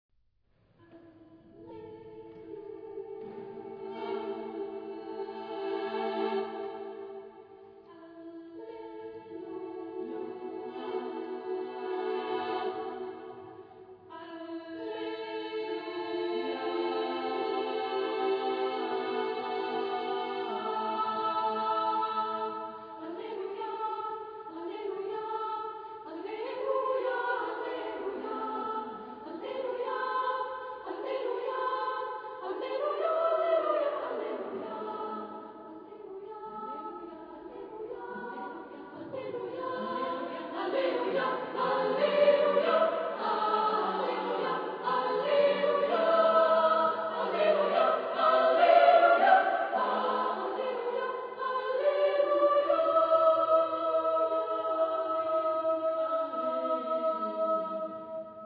Genre-Style-Forme : Sacré ; Acclamation
Caractère de la pièce : rythmé ; jubilatoire
Type de choeur : SSAA  (4 voix égales de femmes )
Tonalité : la (centré autour de)